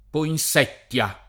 vai all'elenco alfabetico delle voci ingrandisci il carattere 100% rimpicciolisci il carattere stampa invia tramite posta elettronica codividi su Facebook poinsettia [ poin S$ tt L a ] o poinsezia [ poin S$ZZL a ] s. f. (bot.)